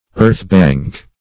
Earthbank \Earth"bank`\ ([~e]rth"b[a^][ng]k`), n.